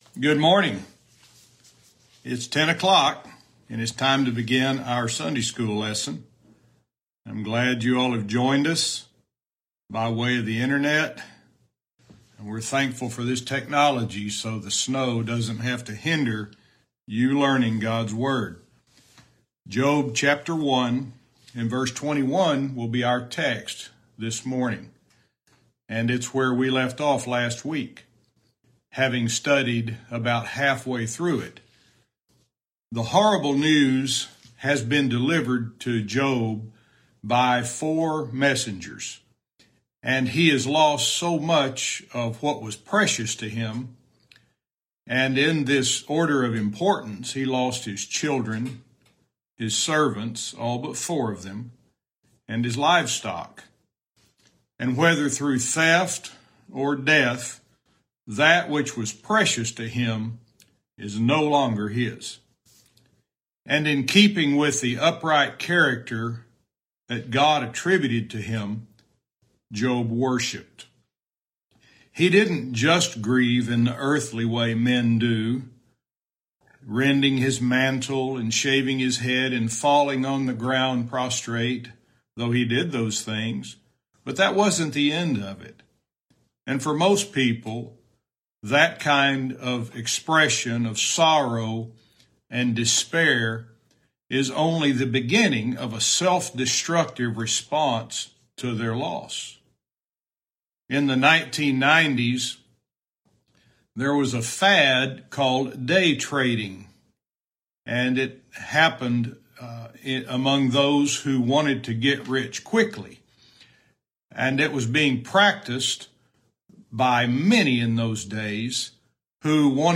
Verse by verse teaching - Job 1:21-2:3